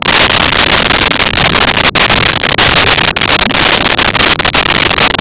Sfx Amb Underwater Loop
sfx_amb_underwater_loop.wav